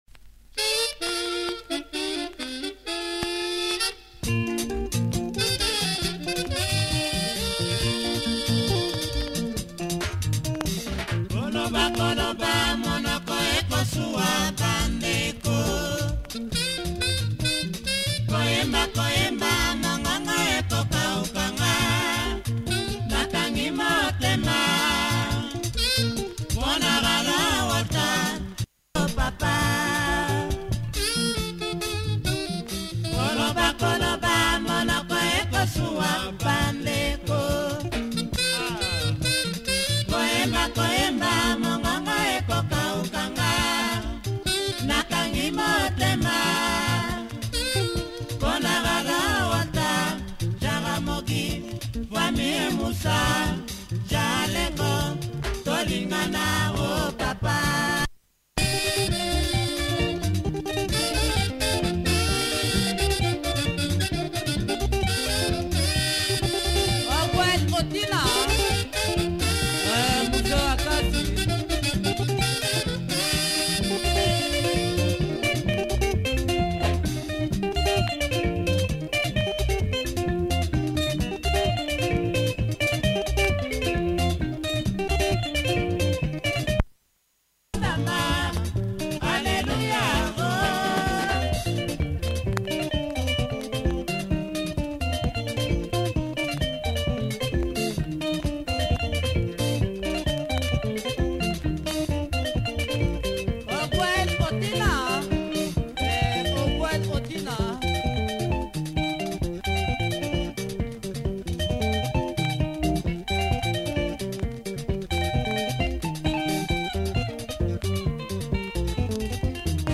lingala song